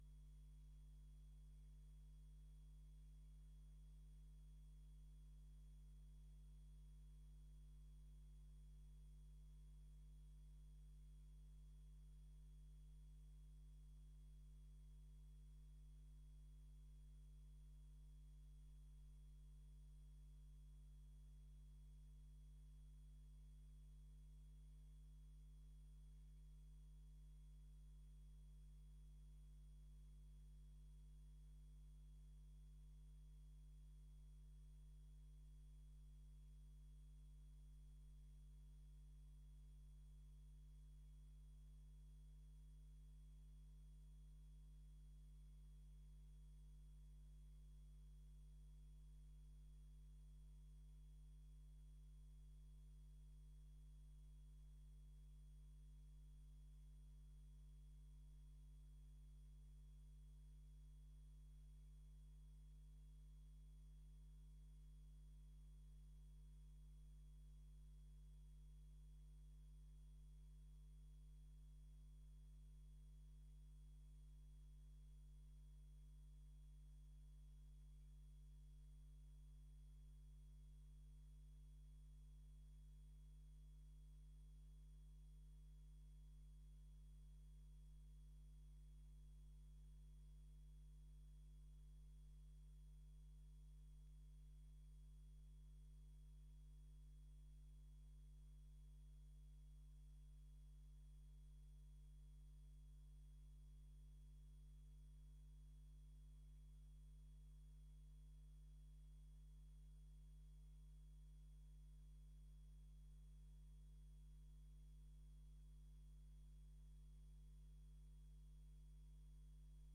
Gemeenteraad 02 september 2024 19:30:00, Gemeente Dalfsen